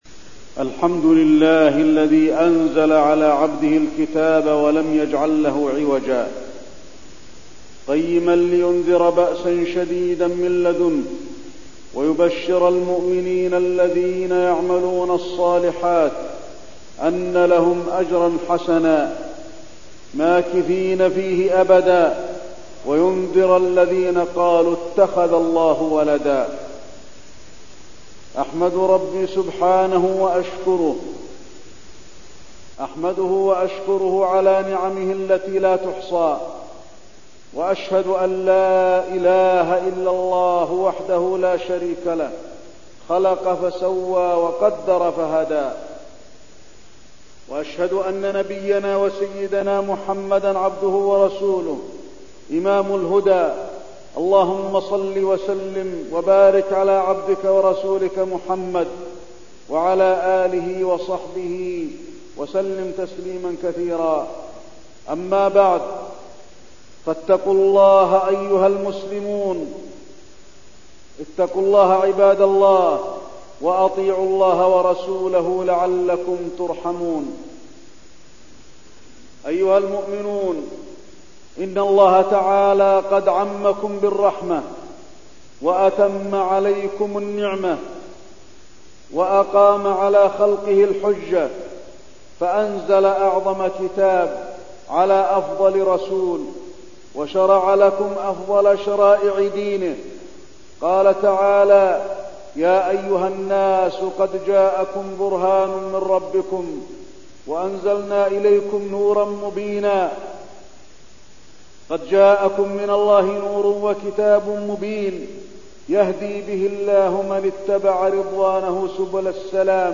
تاريخ النشر ٥ جمادى الأولى ١٤١٣ هـ المكان: المسجد النبوي الشيخ: فضيلة الشيخ د. علي بن عبدالرحمن الحذيفي فضيلة الشيخ د. علي بن عبدالرحمن الحذيفي نعمة القرآن الكريم